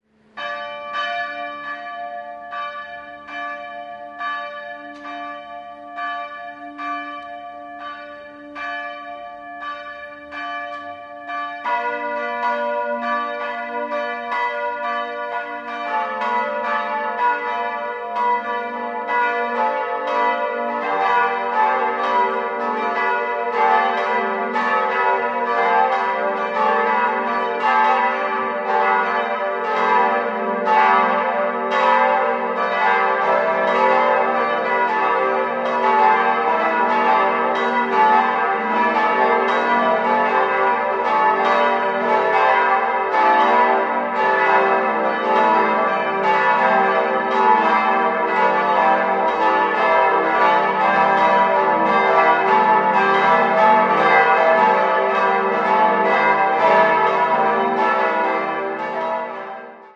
5-stimmiges ausgefülltes und erweitertes E-Moll-Geläute: e'-g'-a'-h'-d''
Die sechste Glocke wird nur als Sterbeglocke einzeln benutzt.